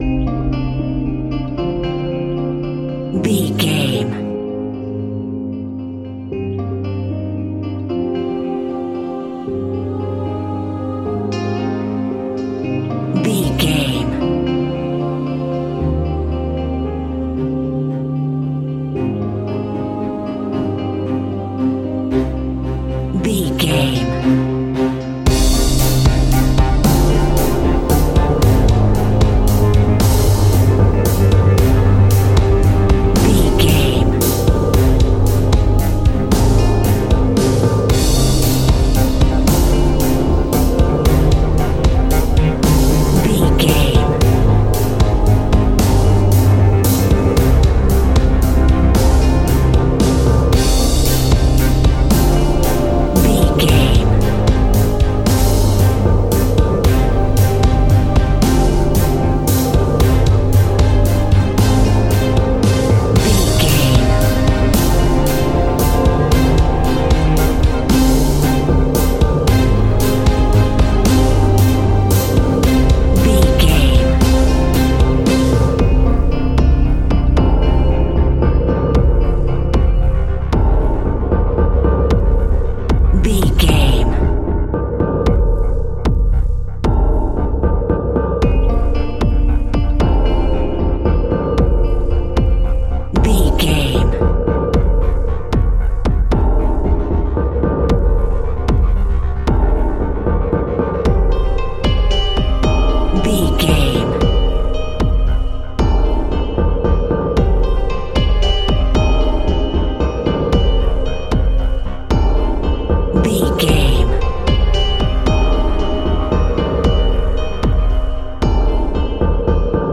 Aeolian/Minor
dramatic
epic
powerful
strings
percussion
synthesiser
brass
violin
cello
double bass